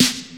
• 80's Crunchy Snare Single Hit B Key 50.wav
Royality free snare sample tuned to the B note. Loudest frequency: 3547Hz
80s-crunchy-snare-single-hit-b-key-50-K3K.wav